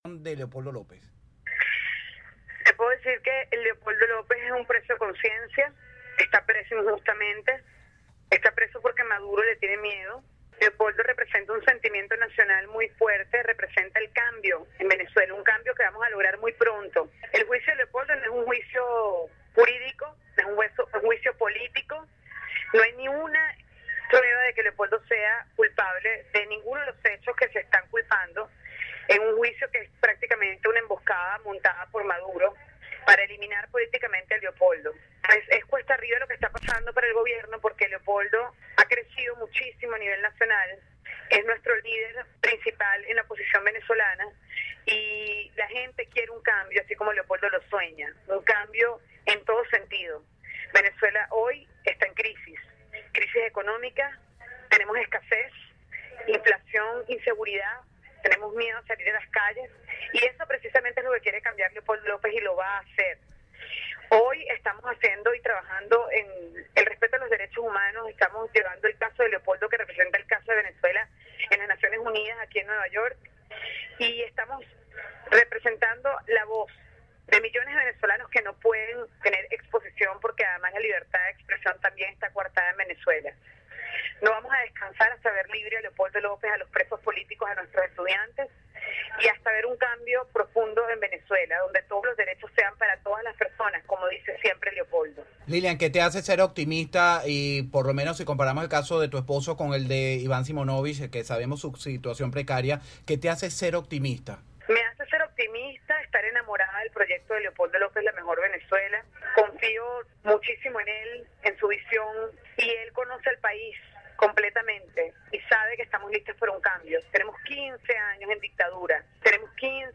Entrevista con Lilian Tintori